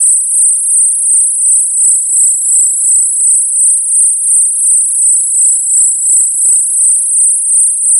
Brummen ≠ Rauschen ≠ Fiepen
Fiepen klingt wie ein hoher Ton, in etwa wie ein „iiii“ oder „piep“. Fiepen stammt häufig von Schaltnetzteilen, PCs, Grafikkarten oder Spulenfiepen.
fiepen_einstreuung.mp3